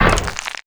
SPLAT_Generic_06_mono.wav